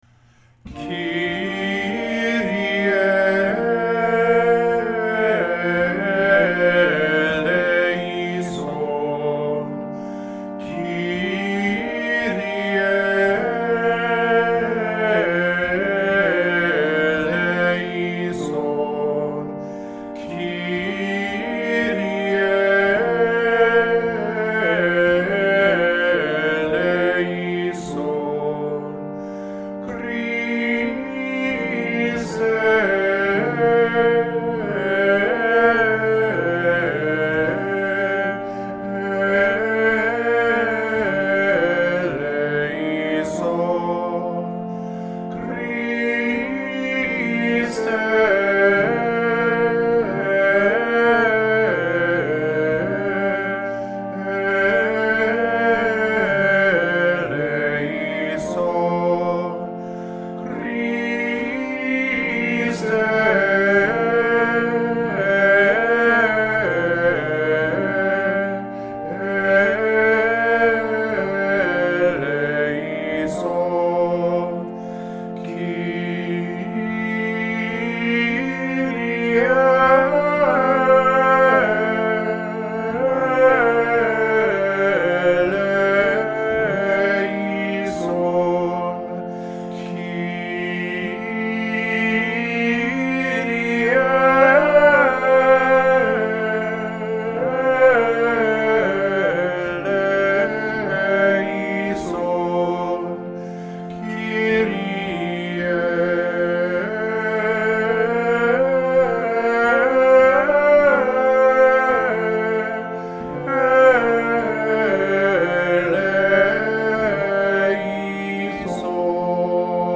Lateinische-Messe-19-AdoremusHymnal_110_kyrie.mp3